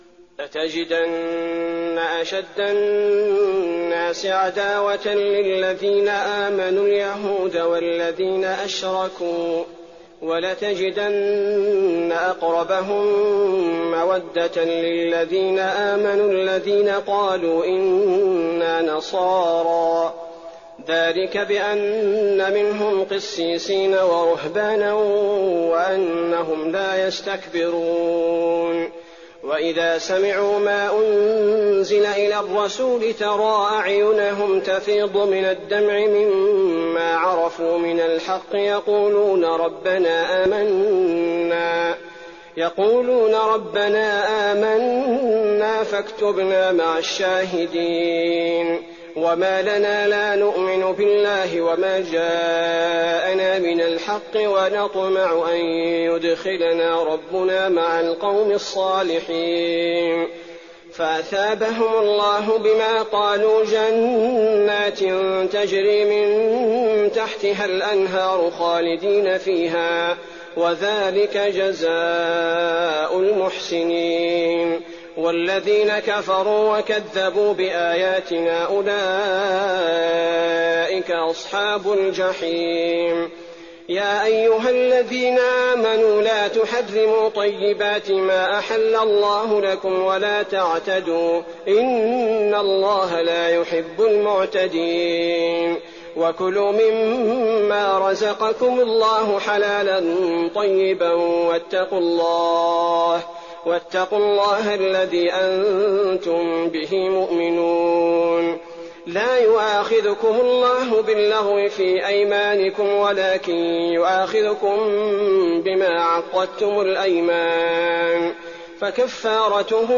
تراويح الليلة السابعة رمضان 1419هـ من سورتي المائدة (82-120) و الأنعام (1-36) Taraweeh 7th night Ramadan 1419H from Surah AlMa'idah and Al-An’aam > تراويح الحرم النبوي عام 1419 🕌 > التراويح - تلاوات الحرمين